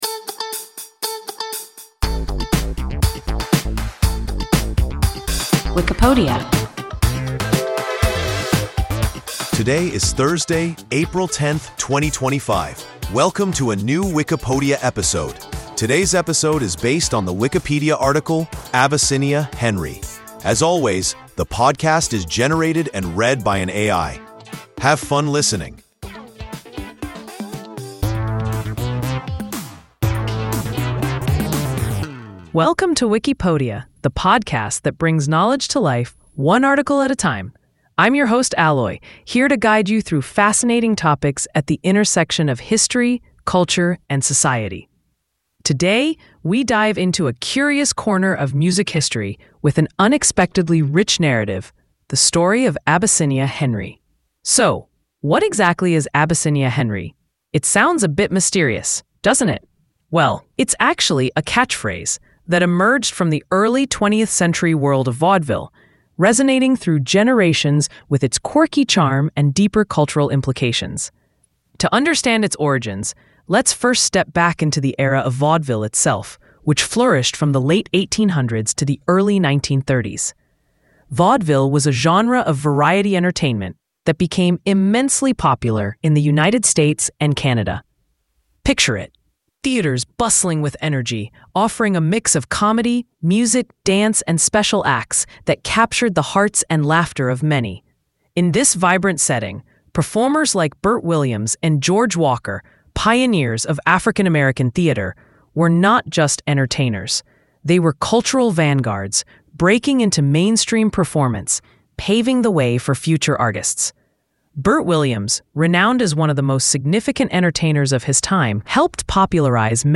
Abyssinia, Henry – WIKIPODIA – ein KI Podcast